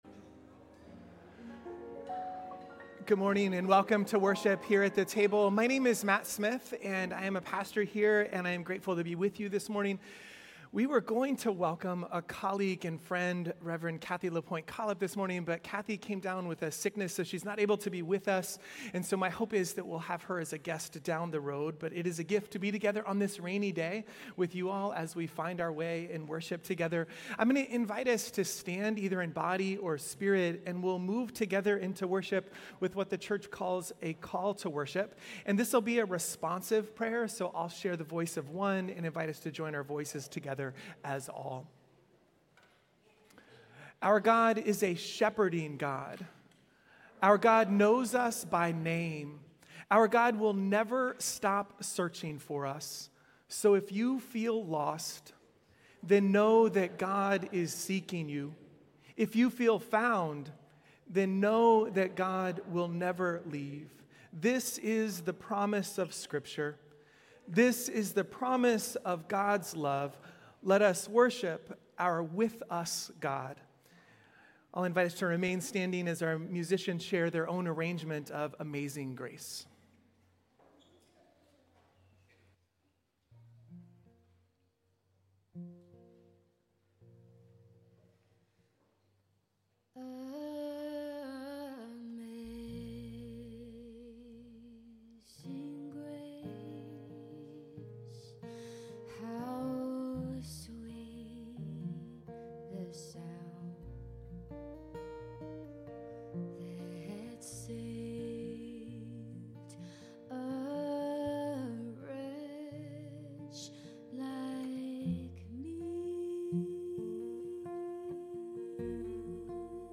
Between Luke Watch Listen Save Our Lenten worship series is inspired by A Sanctified Art and called Everything [In] Between.